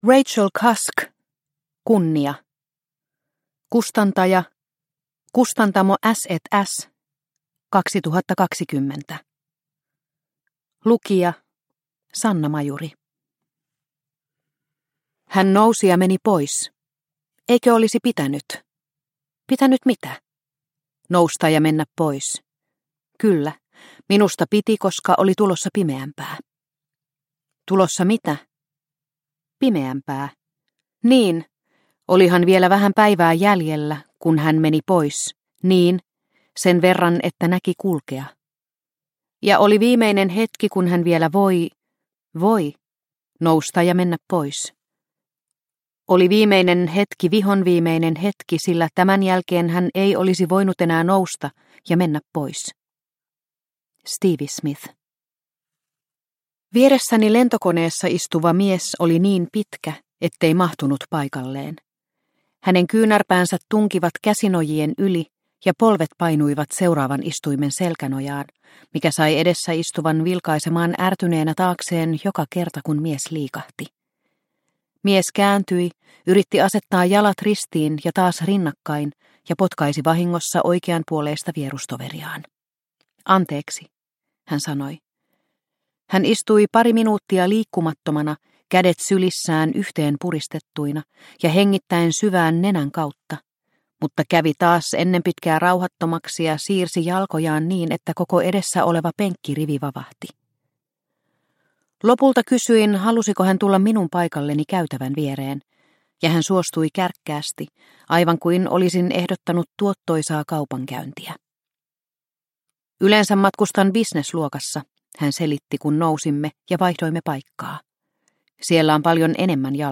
Kunnia – Ljudbok – Laddas ner